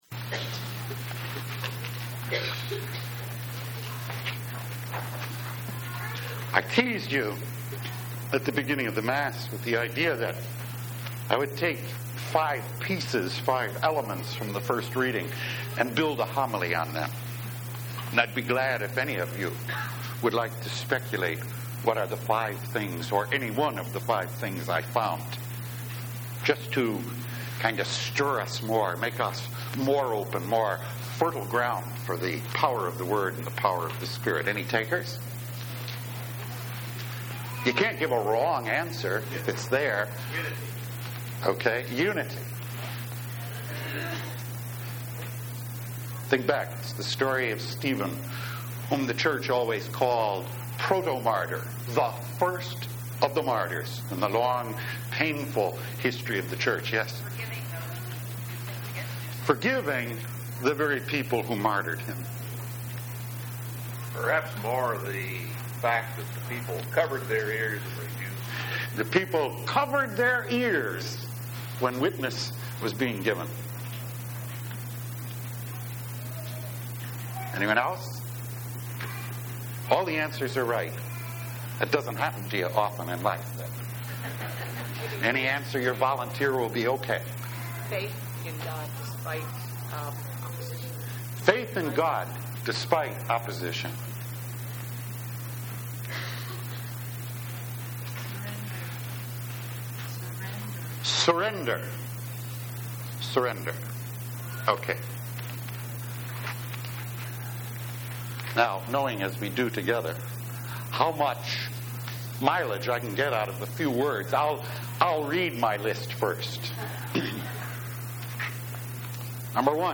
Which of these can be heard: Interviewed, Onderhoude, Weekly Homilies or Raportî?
Weekly Homilies